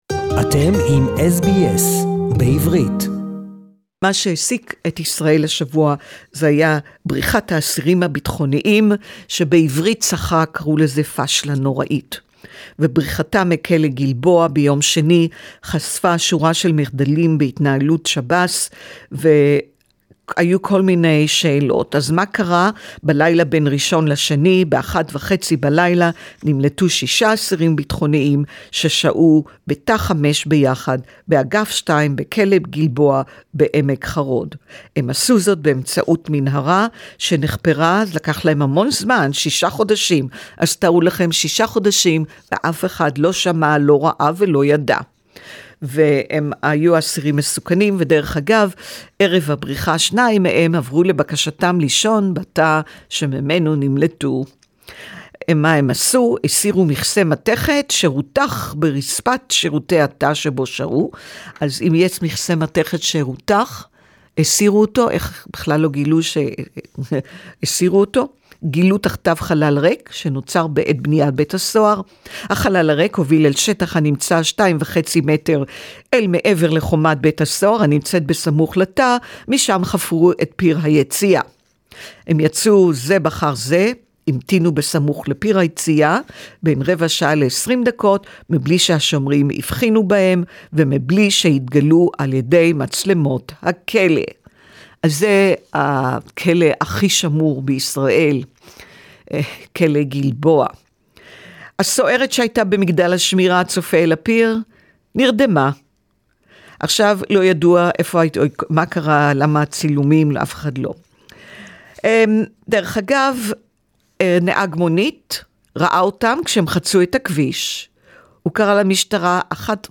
How did 6 Palestinian prisoners manage to escape a high security Jail? SBS report in Hebrew